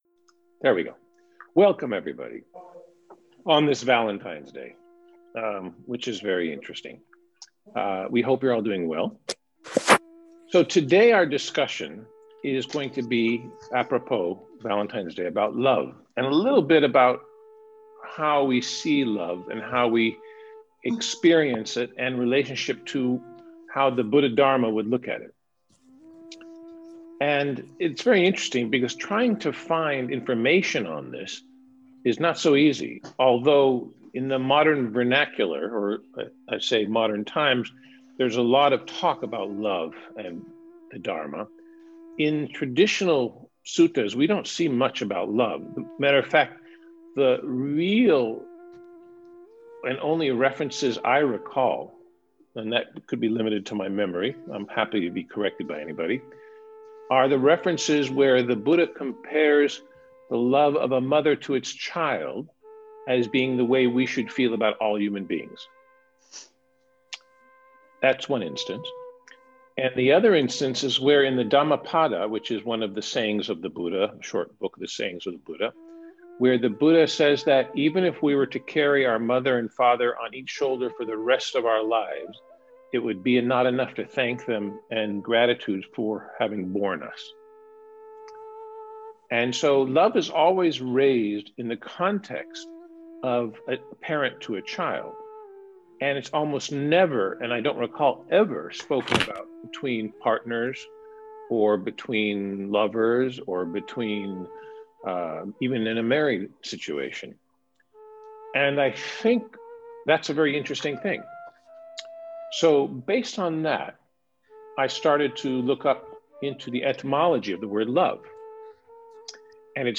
Sunday 14, February, Valentines day for a discourse analysing and learning how the Mahatanhashankaya Sutta, can be of assistance in the Subject of Love and Relationship. Open to all registered in the Path to Wisdom Community-this is a 20 minute excerpt of the 90. minute discourse.